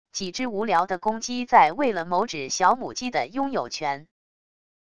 几只无聊的公鸡在为了某只小母鸡的拥有权wav音频